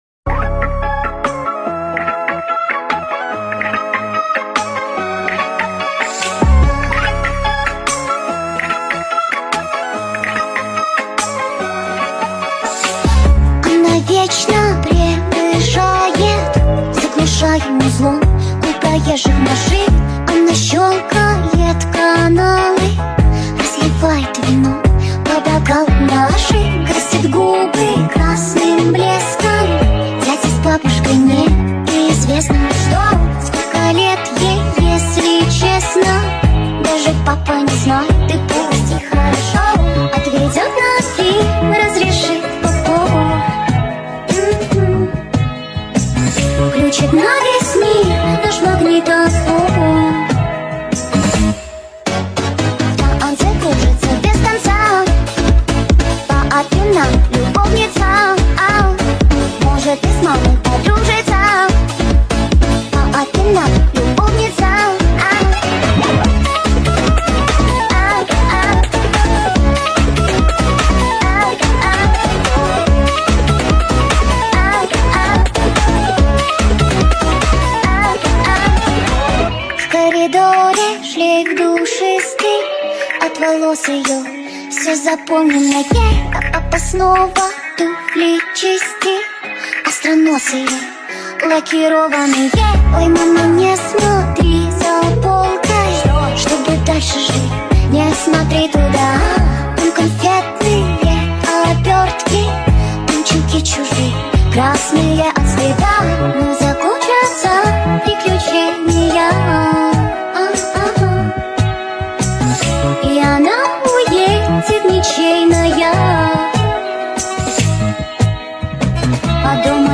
• Качество: 320 kbps, Stereo
sped up remix
тик ток ремикс